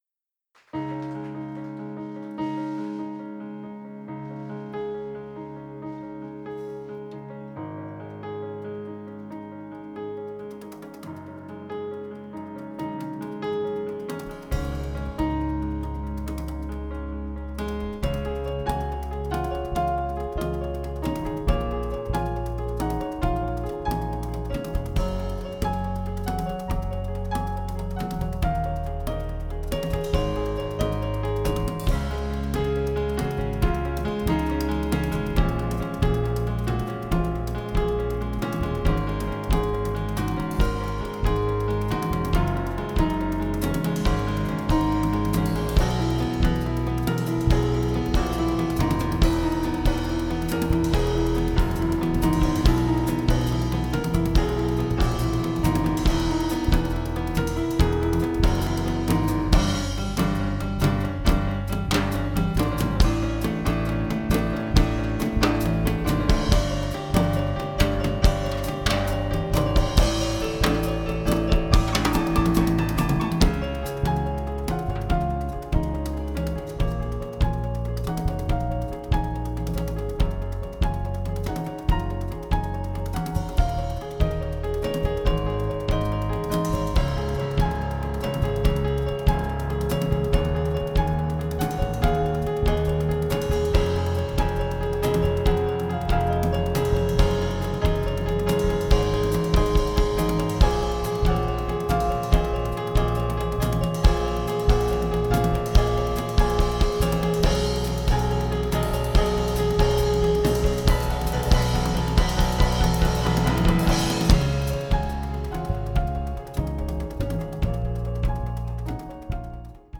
Experimental instrumental music
sax, keyboards